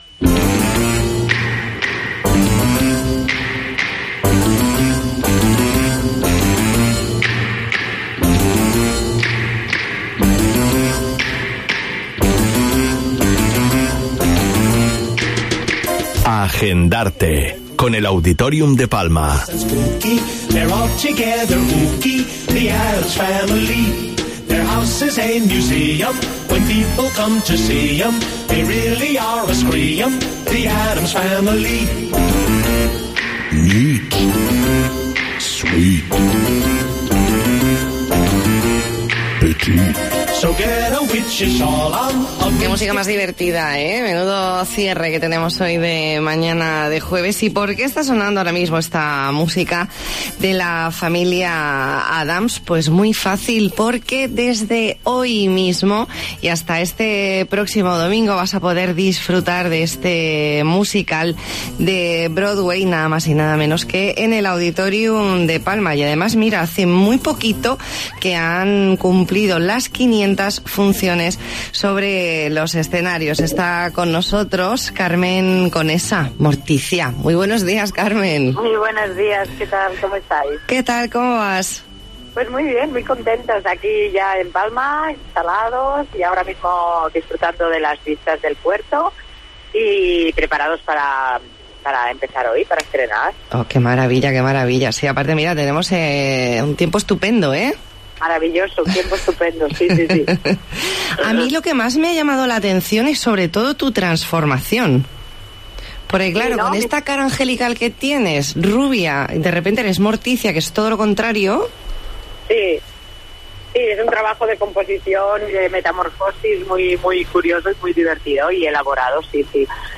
ENTREVISTA
Hablamos con la actriz Carmen Conesa, quien interpreta a la espeluznante y sexy Morticia Addams en este musical de Broadway que acaba de superar las 500 funciones en distintos escenarios de nuestro país. Entrevista en La Mañana en COPE Más Mallorca, jueves 28 de marzo de 2019.